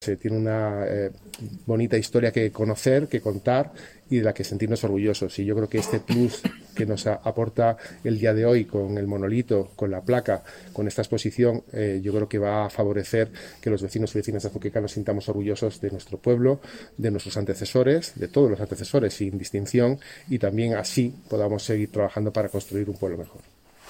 Declaraciones del presidente de las Cortes, Pablo Bellido